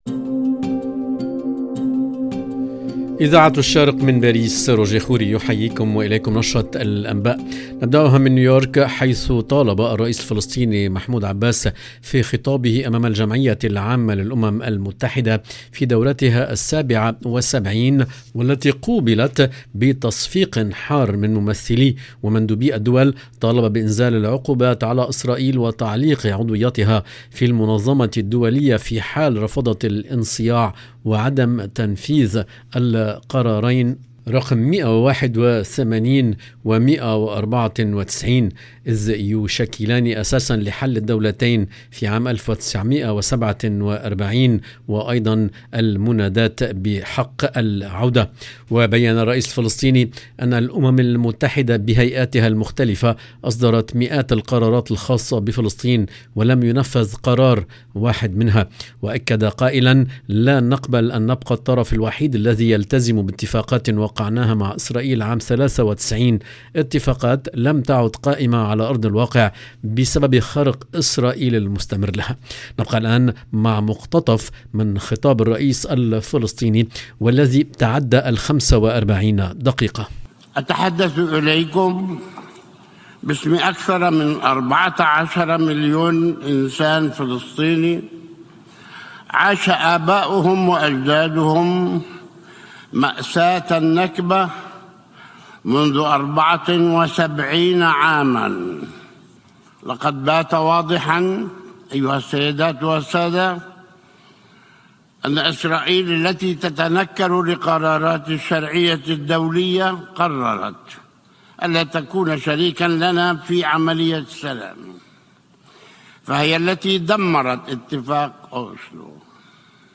LE JOURNAL EN LANGUE ARABE DU SOIR DU 24/09/22
نستمع الى مقتطف من كلمته امام الجمعية العامة للأمم المتحدة في نيويورك... المرصد السوري يعلن ارتفاع عدد ضحايا مركب الموت اللبناني قبالة شاطئ طرطوس إلى 91 قتيلا و50 مفقودا...